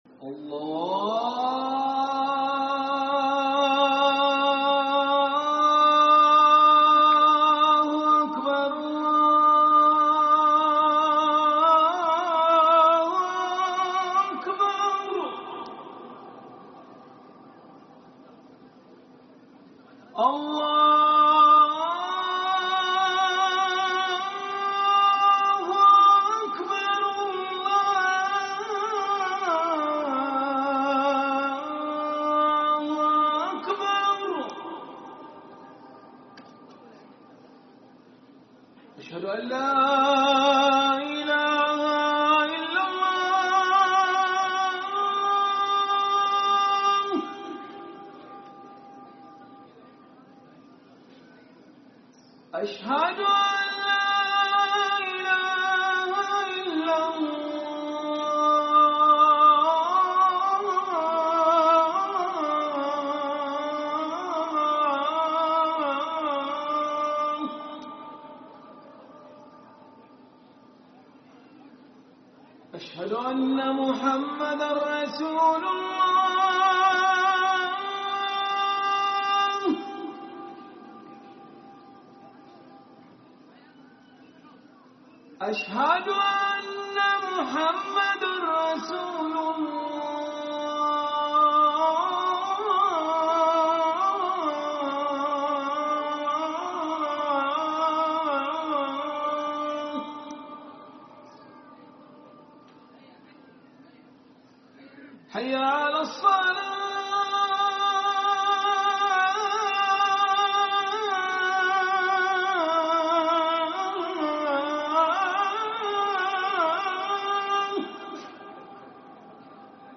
mecca-athan-fajr.mp3